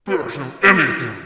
Piercer voice sample 2